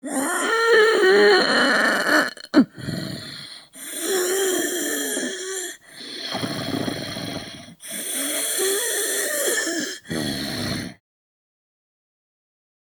ZombieMaleBreathi EVL042101.wav